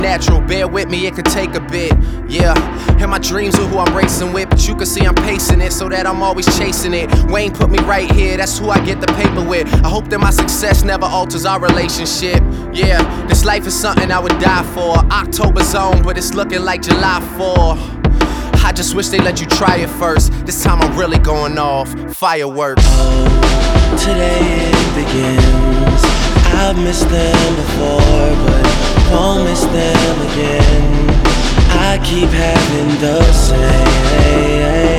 Жанр: Хип-Хоп / Рэп / Рок / R&B / Соул